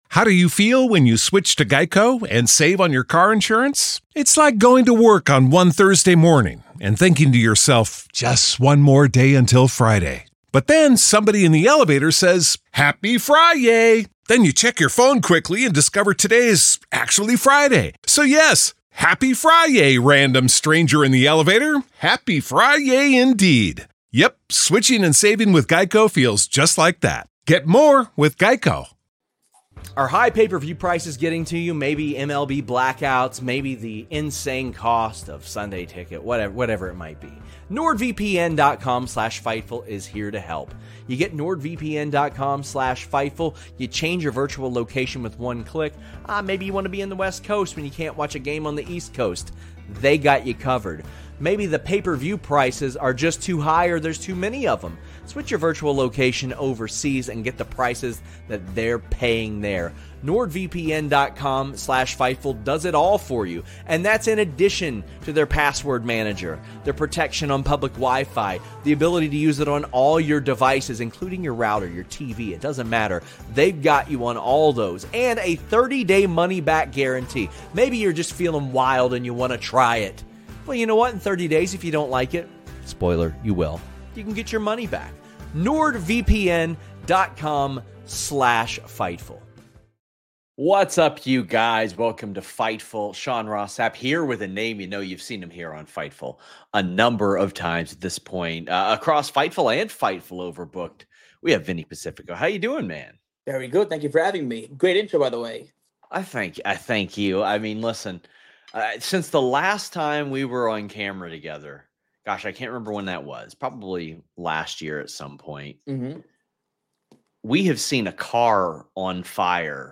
Shoot Interviews Nov 18